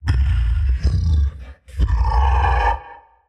Vo_primal_beast_primal_happy_02.mp3